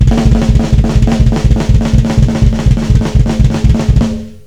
Black Metal Drum Rudiments
Fill 1 - Quads
When you play this in a fill it sounds like you're really blazing down the toms!